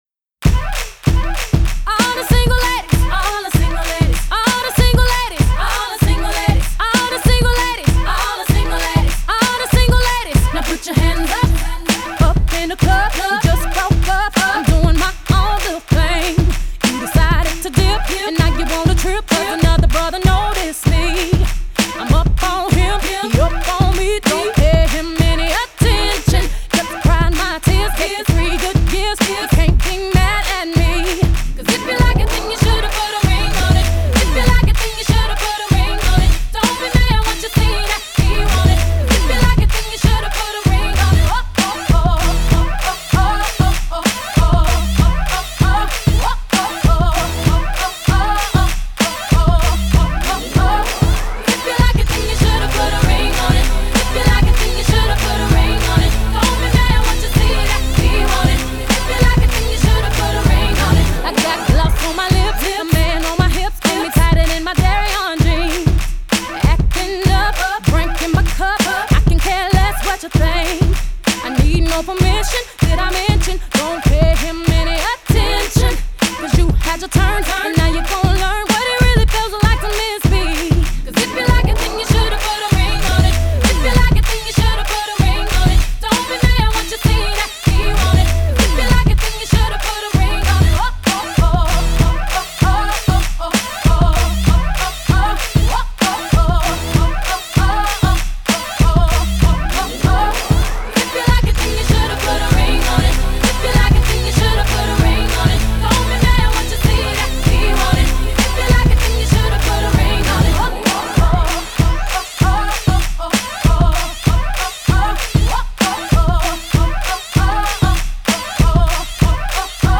Pop 2000er